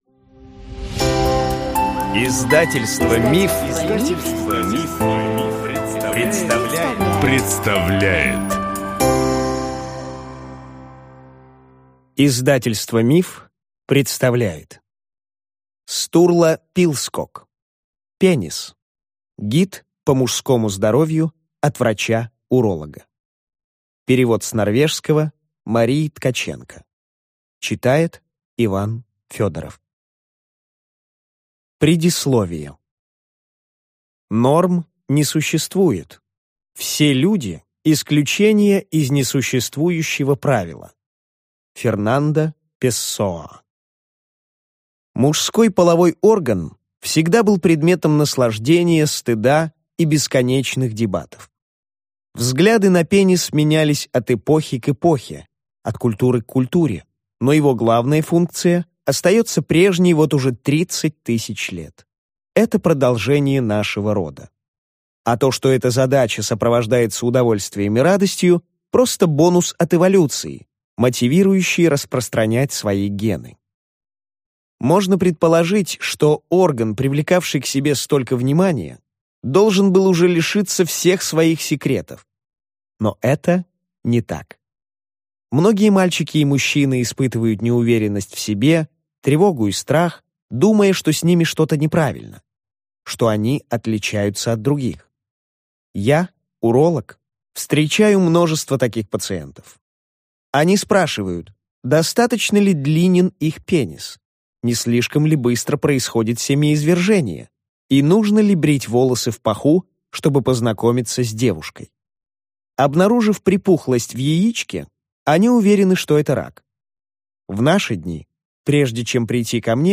Аудиокнига Penis. Гид по мужскому здоровью от врача-уролога | Библиотека аудиокниг